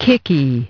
Transcription and pronunciation of the word "kicky" in British and American variants.